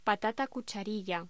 Locución: Patata cucharilla
voz